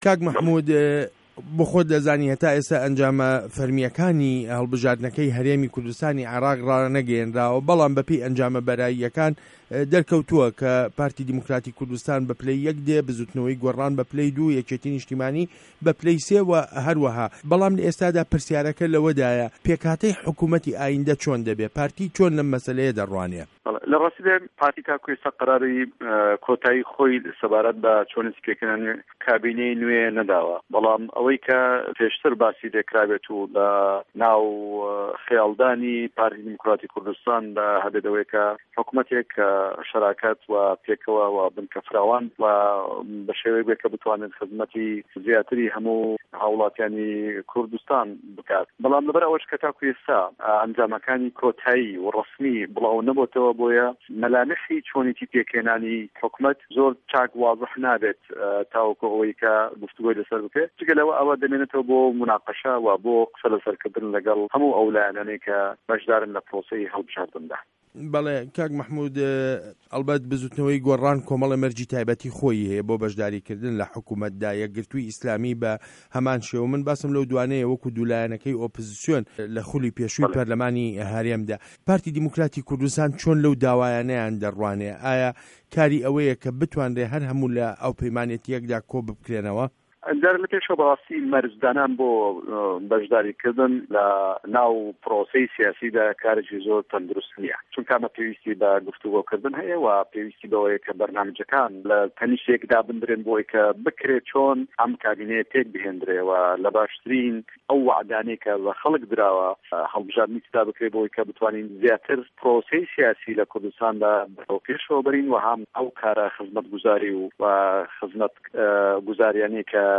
وتووێژ له‌گه‌ڵ مه‌حمود موحه‌مه‌د